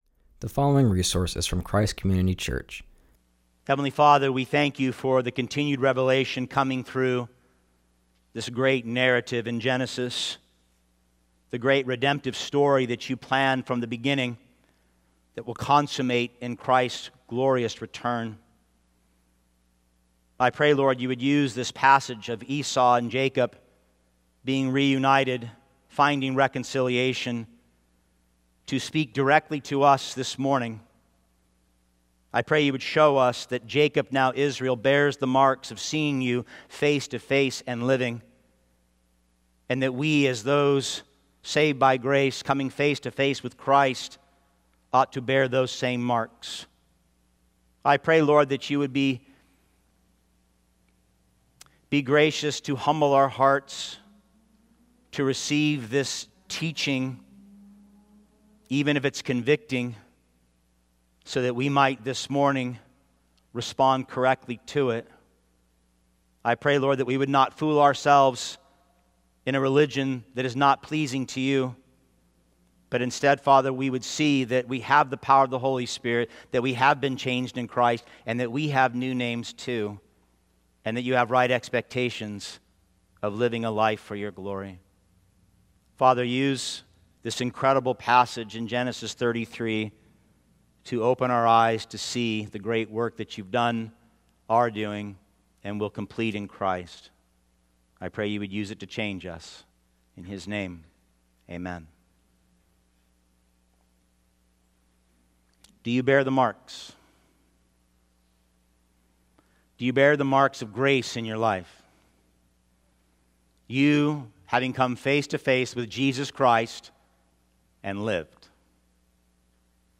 continues our series and preaches from Genesis 33:1-20.